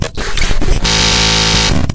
Glitch-Sound2.mp3